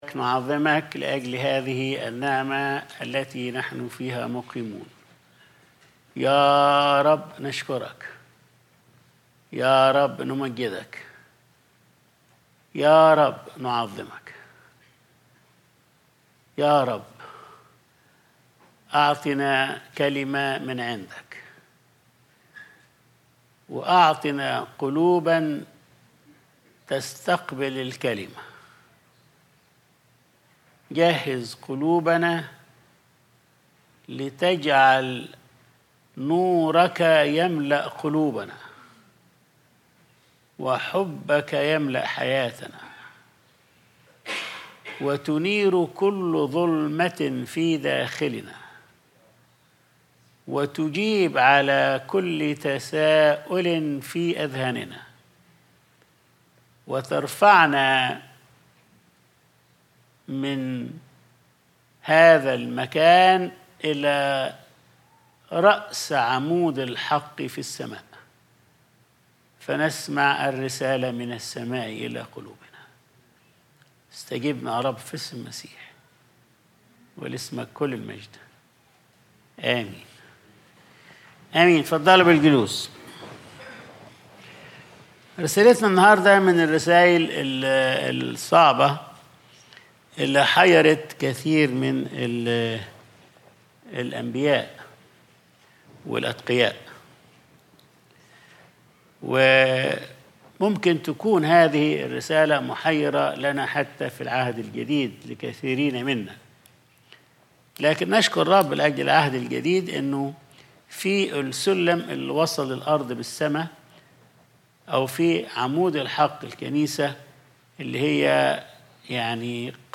Sunday Service | الكل على ما للكل